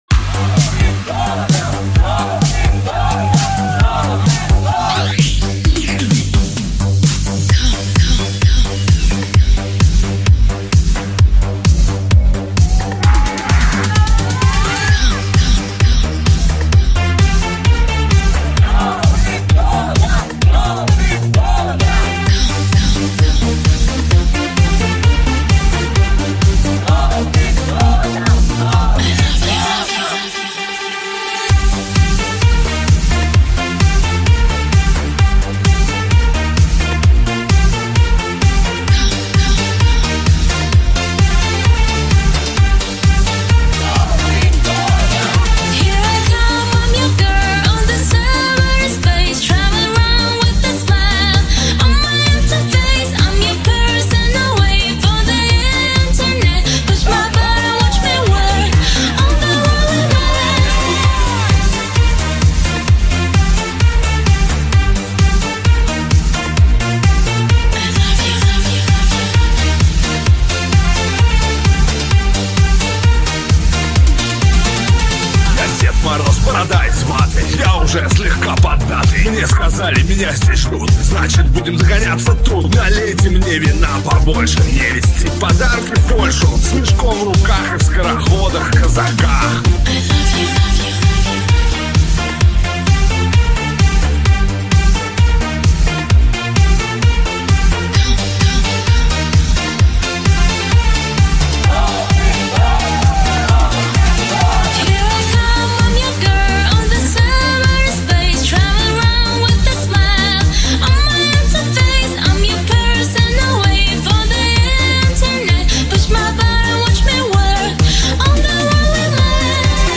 EURODANCE_2024.mp3